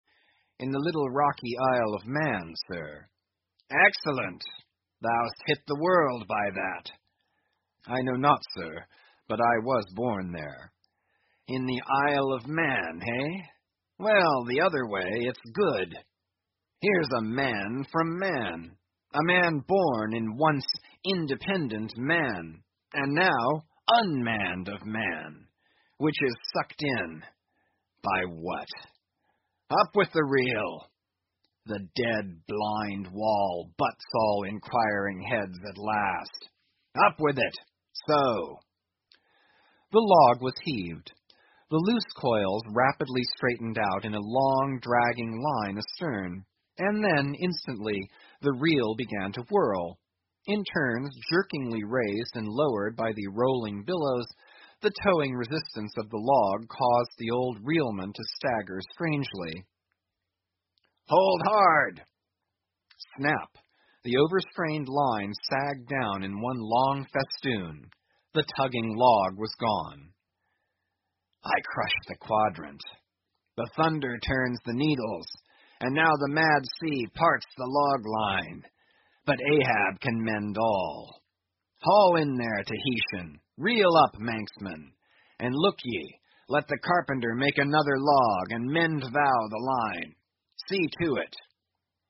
英语听书《白鲸记》第965期 听力文件下载—在线英语听力室